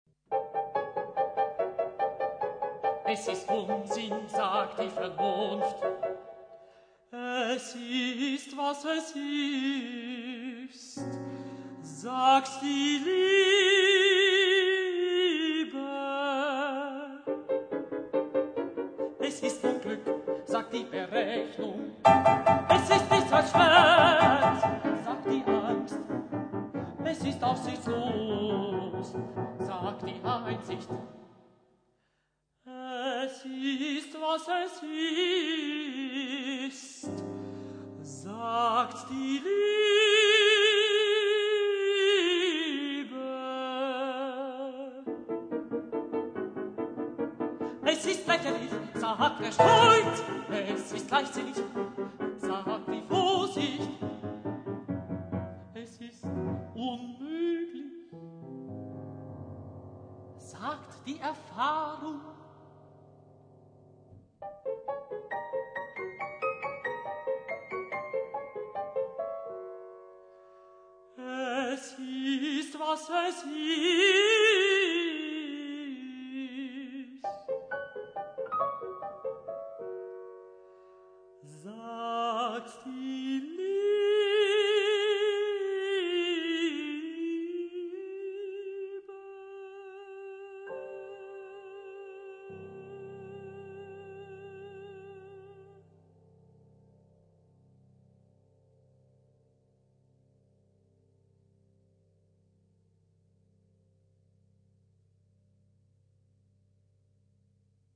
für Tenor und Klavier